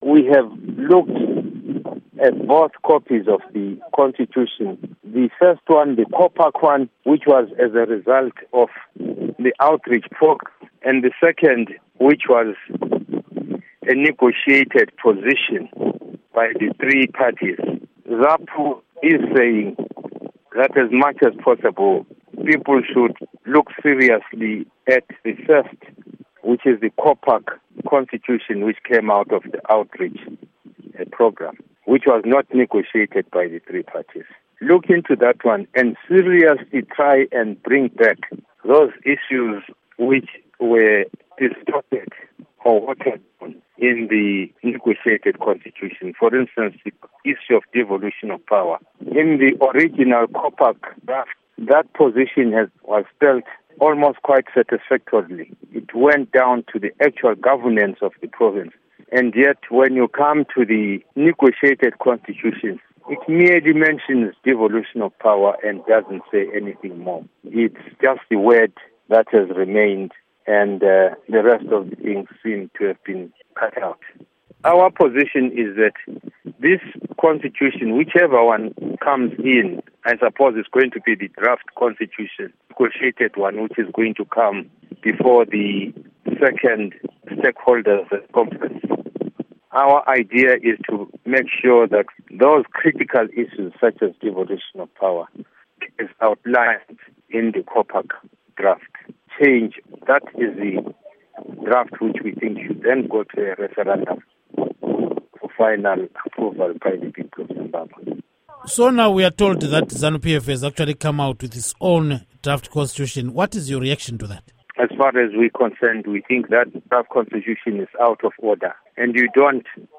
Embed share Interview With Dumiso Dabengwa by VOA Embed share The code has been copied to your clipboard.